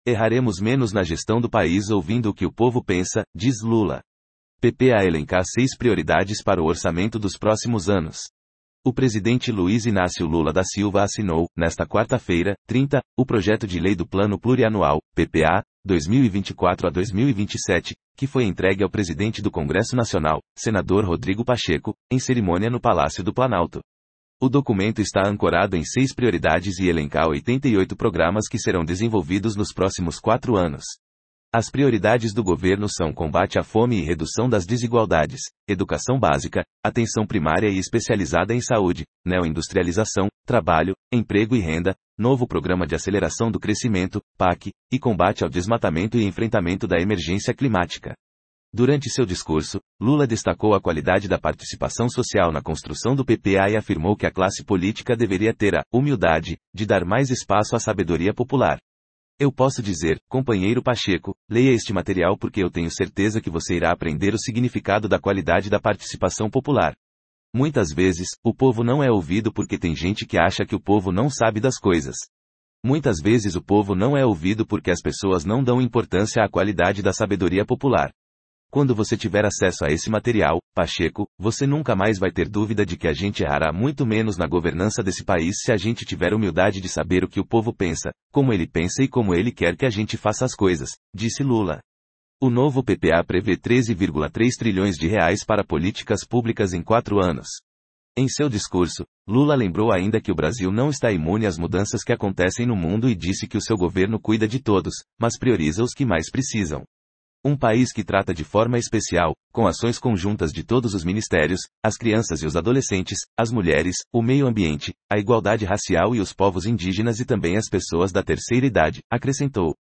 O presidente Luiz Inácio Lula da Silva assinou, nesta quarta-feira (30), o projeto de lei do Plano Plurianual (PPA) 2024-2027, que foi entregue ao presidente do Congresso Nacional, senador Rodrigo Pacheco, em cerimônia no Palácio do Planalto.
Durante seu discurso, Lula destacou a qualidade da participação social na construção do PPA e afirmou que a classe política deveria ter a “humildade” de dar mais espaço à sabedoria popular.